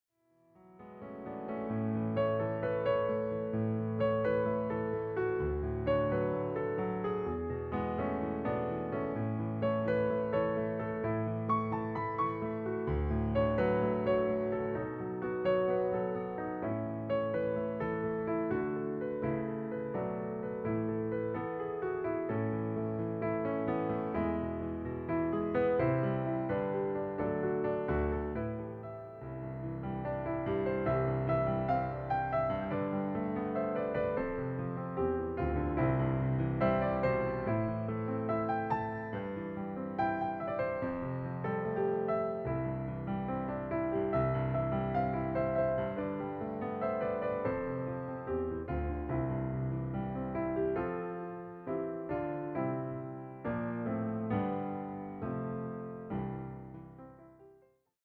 interpreted through solo piano.
intimate and quietly expressive